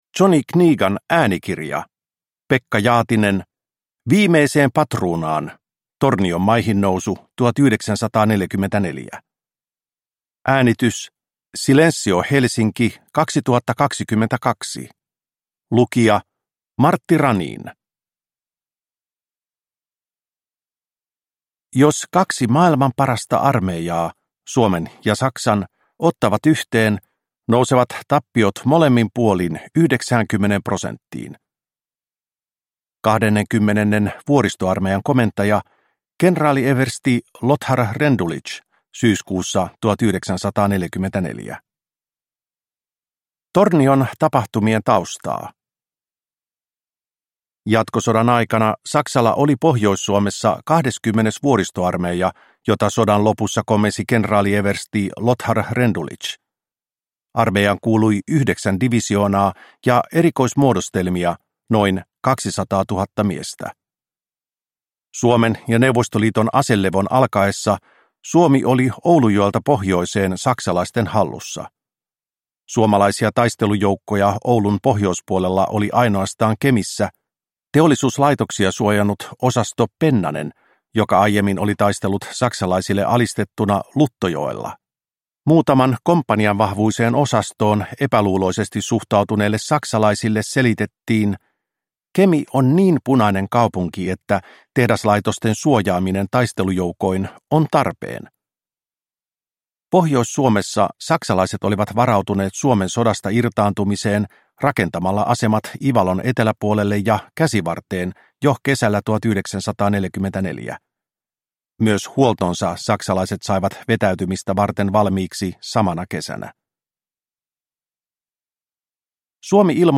Viimeiseen patruunaan – Ljudbok